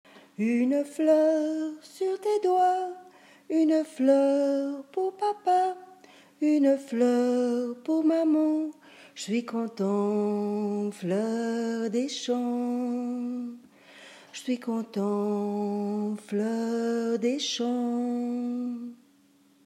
Merci de votre indulgence pour les enregistrements improvisés !
Chanson :